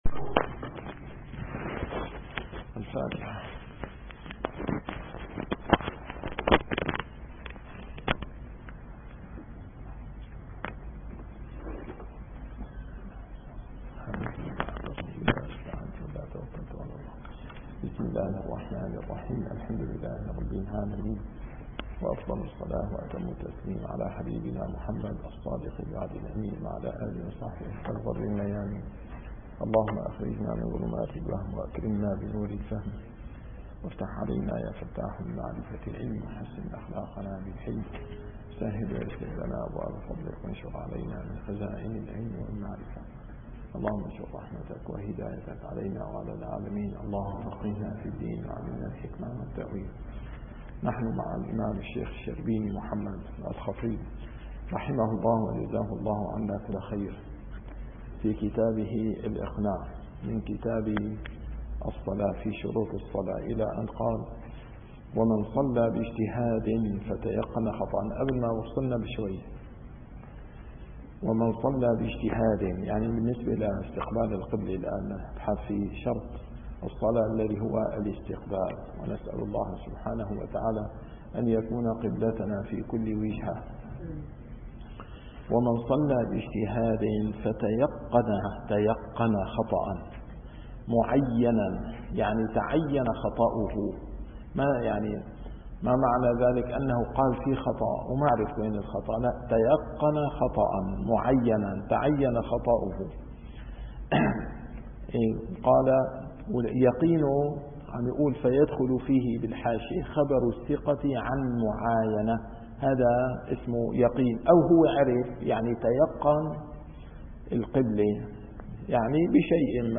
- الدروس العلمية - الفقه الشافعي - كتاب الإقناع - تتمة شروط الصلاة (استقبال القبلة) + أركان الصلاة (النية)